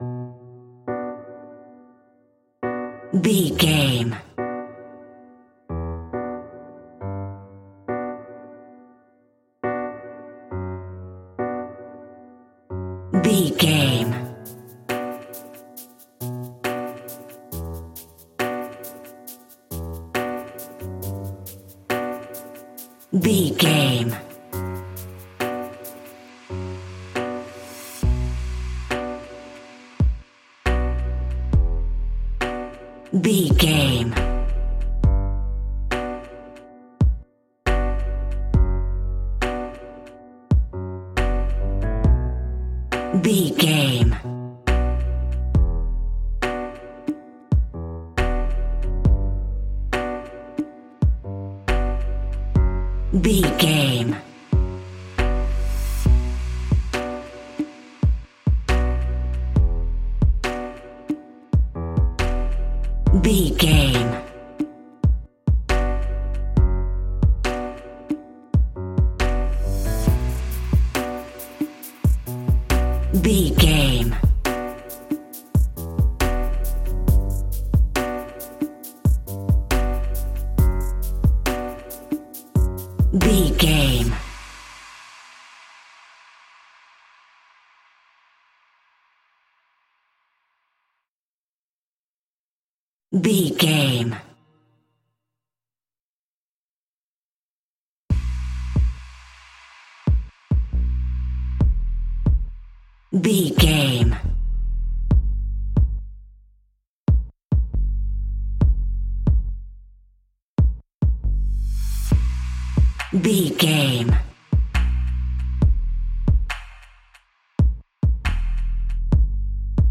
Aeolian/Minor
groovy
dreamy
tranquil
smooth
drum machine
piano
house
synth drums
synth leads
synth bass